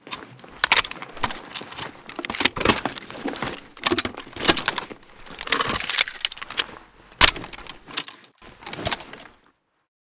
pkm_reload.ogg